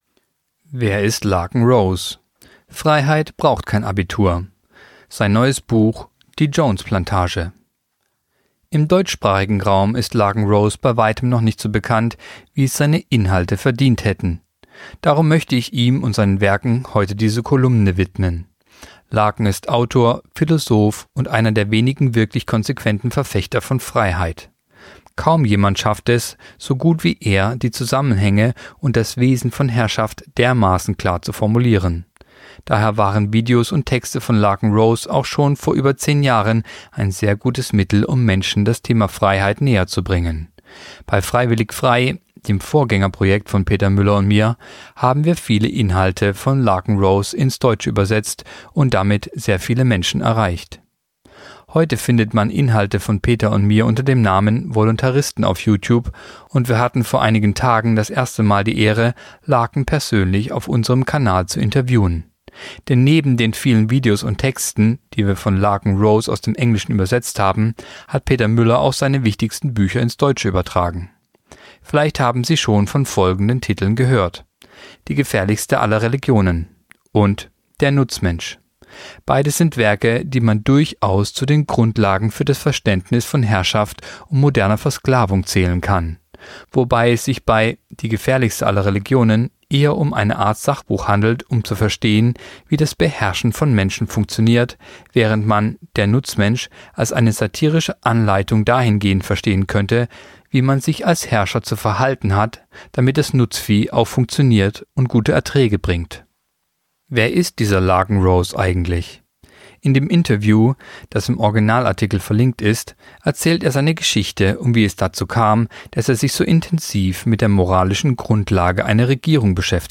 Kolumne der Woche (Radio)Freiheit braucht kein Abitur!